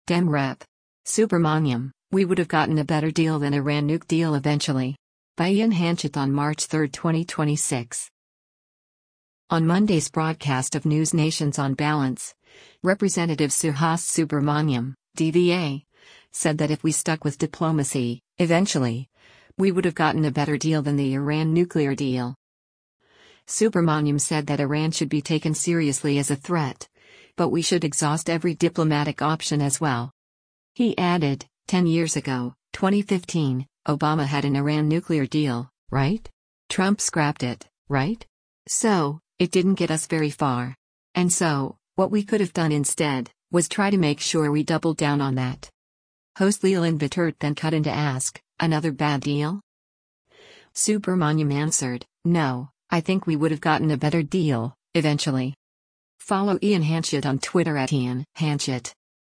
On Monday’s broadcast of NewsNation’s “On Balance,” Rep. Suhas Subramanyam (D-VA) said that if we stuck with diplomacy, “eventually”, “we would’ve gotten a better deal” than the Iran nuclear deal.
Host Leland Vittert then cut in to ask, “Another bad deal?”